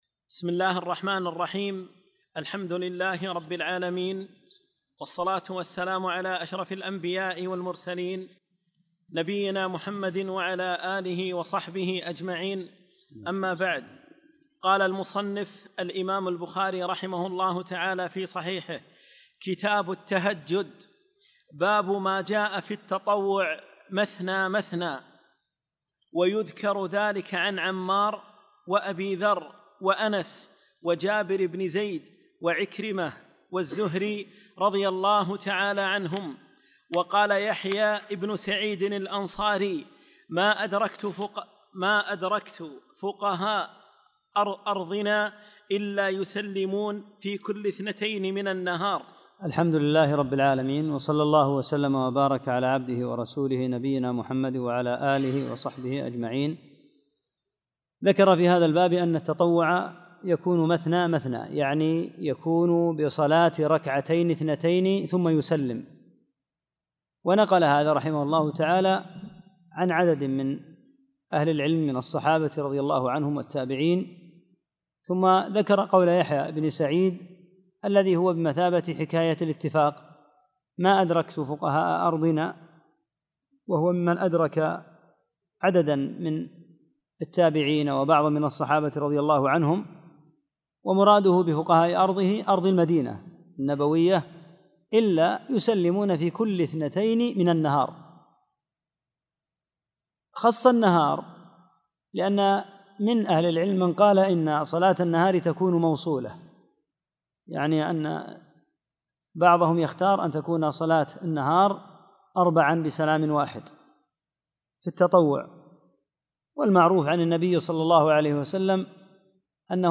4- الدرس الرابع